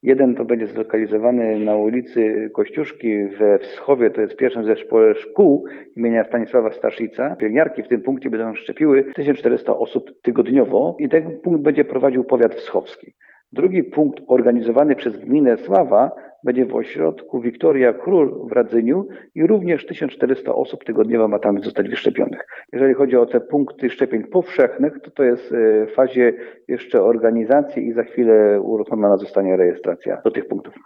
O szczegółach mówi Andrzej Bielawski, wschowski starosta.